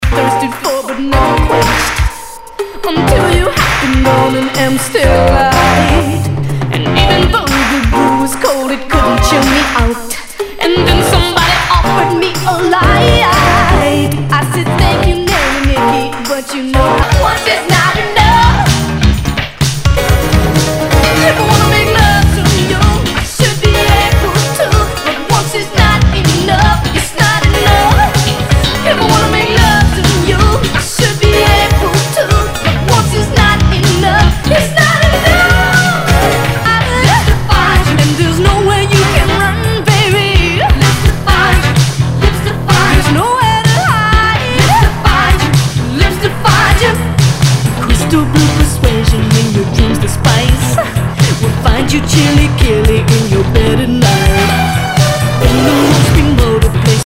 SOUL/FUNK/DISCO
全体にチリノイズが入ります